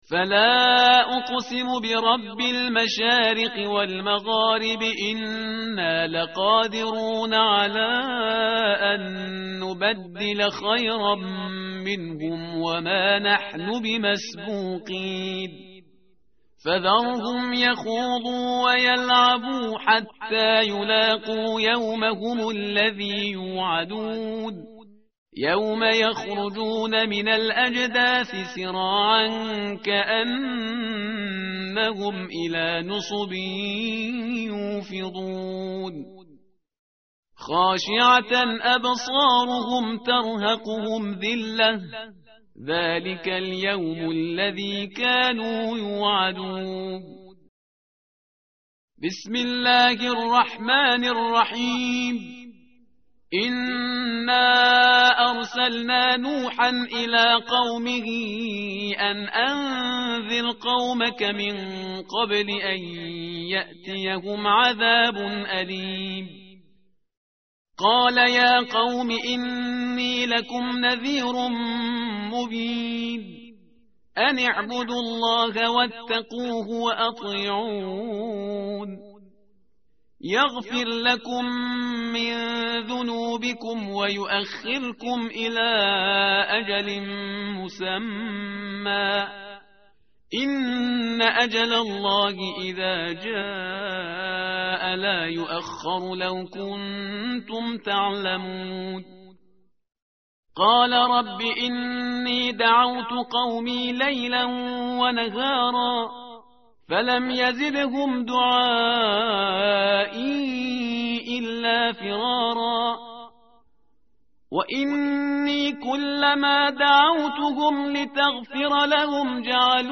tartil_parhizgar_page_570.mp3